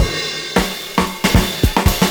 112CYMB06.wav